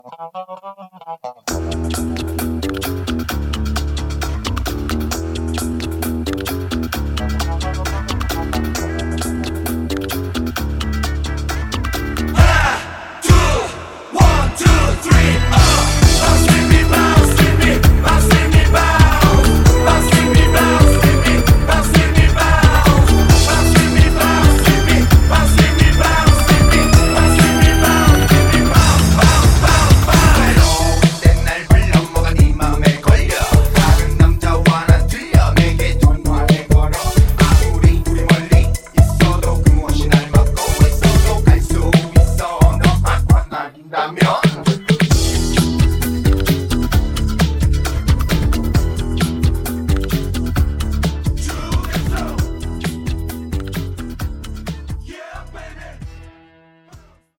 음정 -1키 3:45
장르 가요 구분 Voice MR